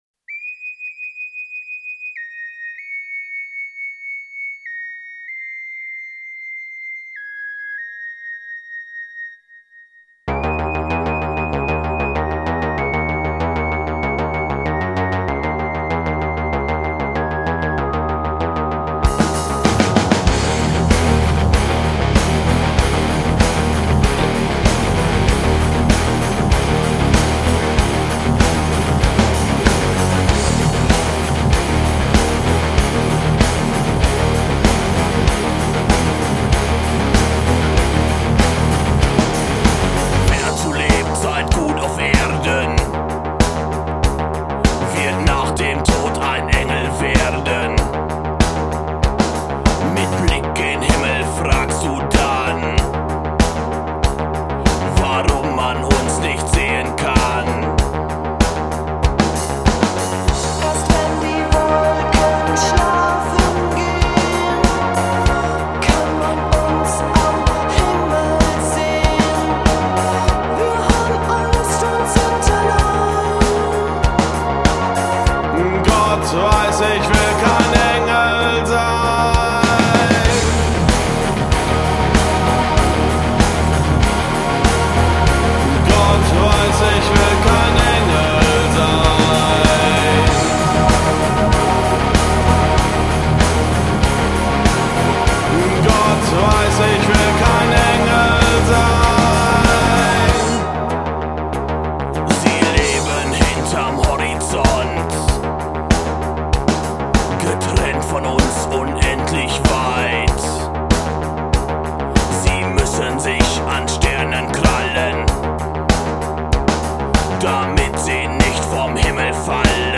Rock-Coverband
drei verschiedenen Frontgesänge
Demo Songs: